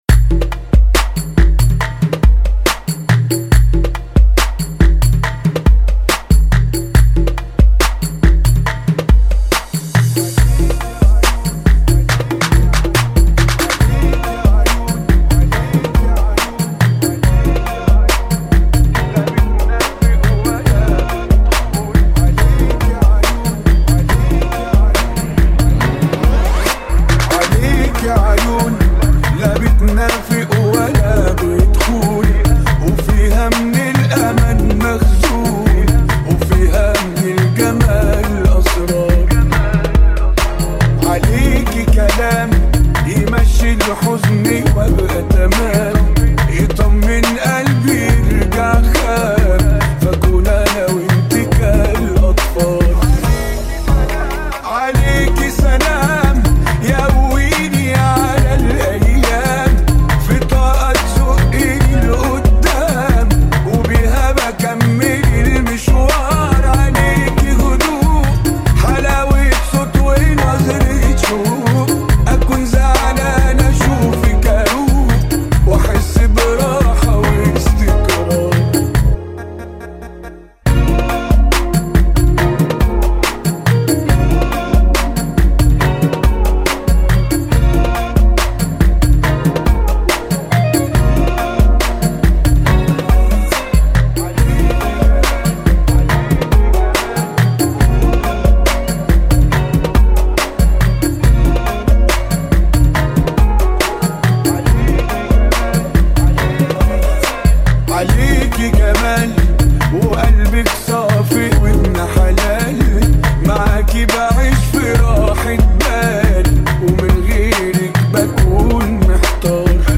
[ 70 bpm ]